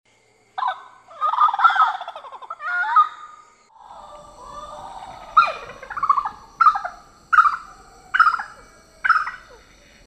Vi finder her blandt andet de mærkelige buskhøns, også kaldet talegallahøns, som lader deres æg blive ruget af gæringsvarmen fra enorme dynger af rådnende blade. Arterne har meget forskellige stemmer, sammenlign for eksempel en fra Filippinerne (03) og Australien